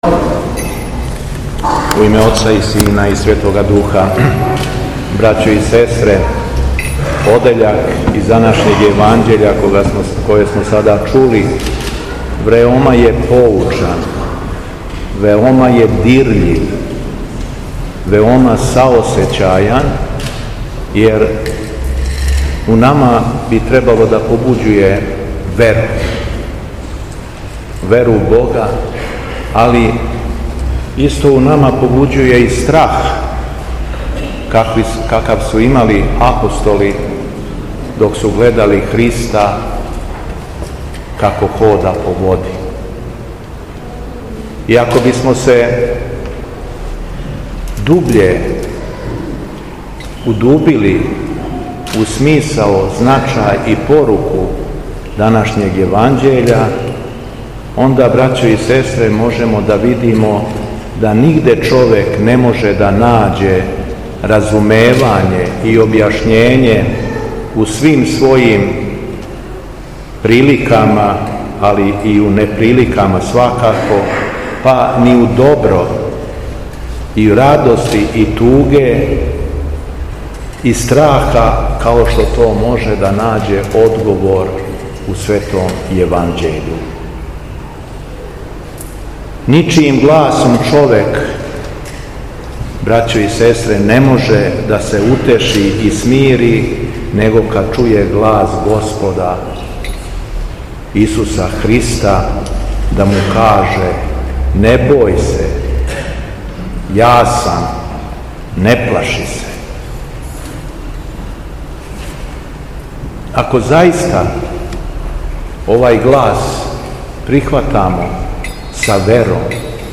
Беседа Његовог Високопреосвештенства Митрополита шумадијског г. Јована
Након прочитаног зачала из Светог Јеванђеља Високопреосвећени Митрополит обратио се верном народу надахнутом беседом: